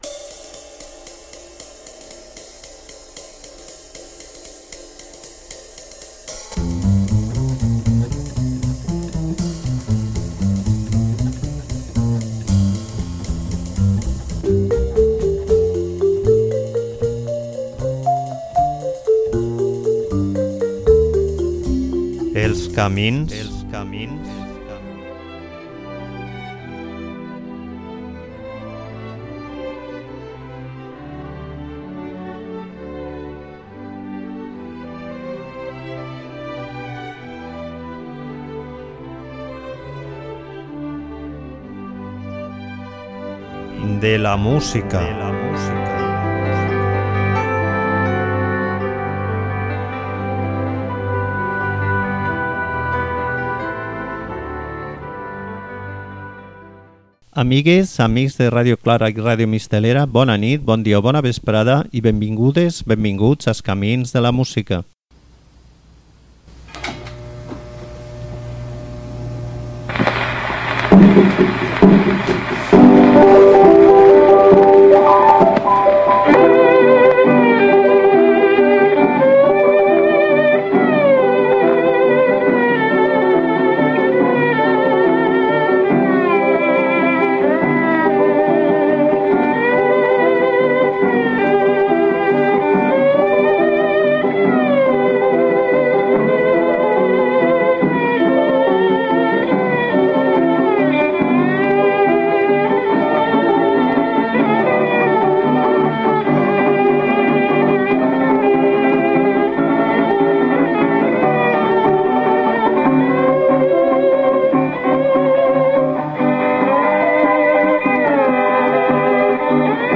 Música deliciosa interpretada de manera impol·luta i un swing abassegador.